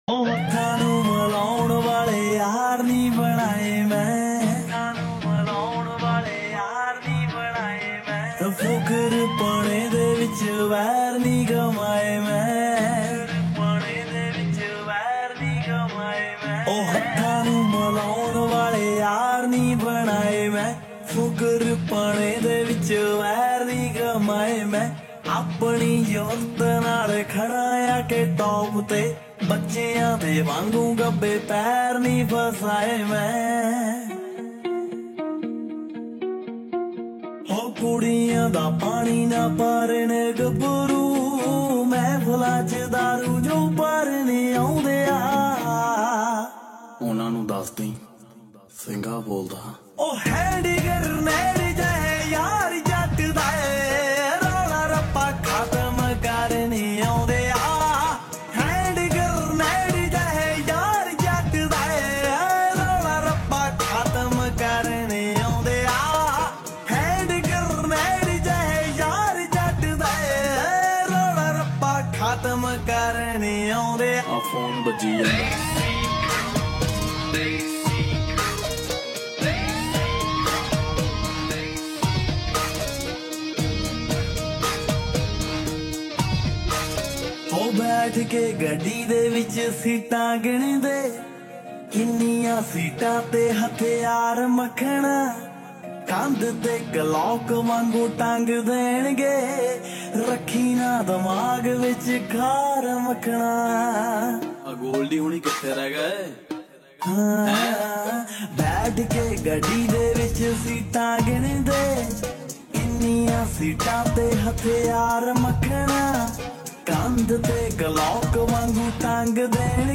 slow and reverd